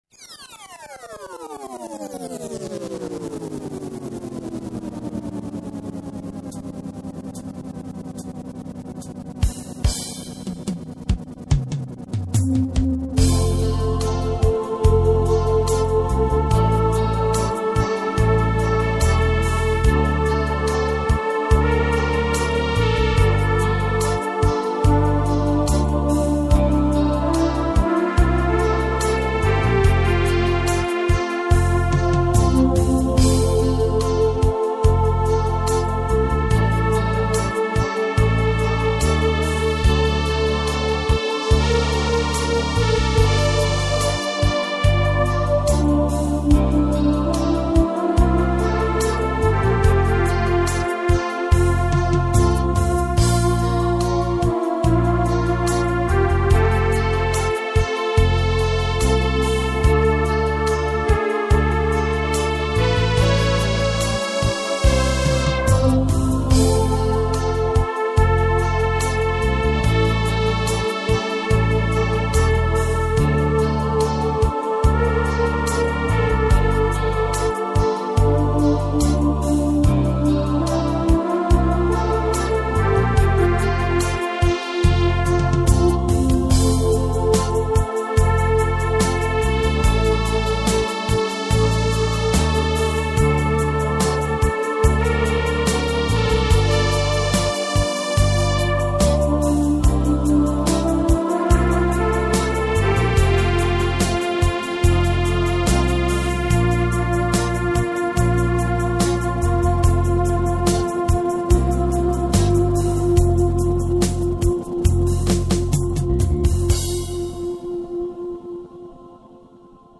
Keyboard-Synthesizer